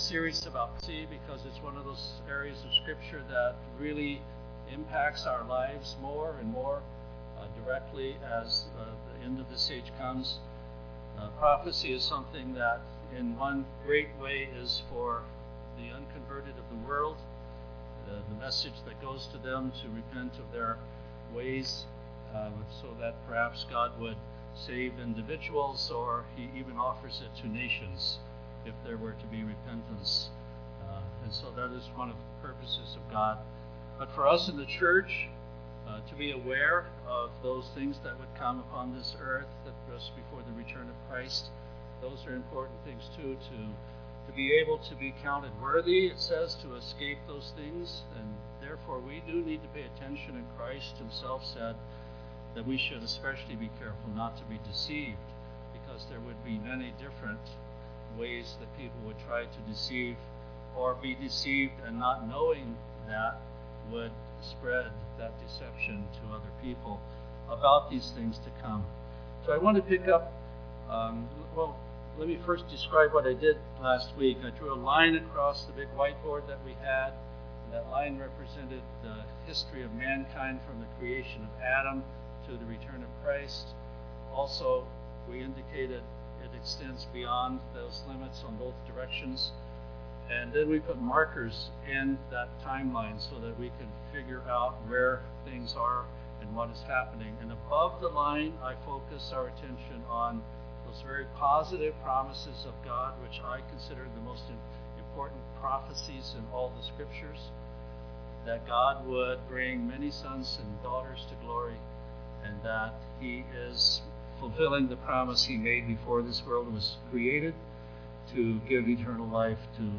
(Note: the first 23 minutes of the audio contains hum and low volume due to interference.)
Given in Tacoma, WA
UCG Sermon Studying the bible?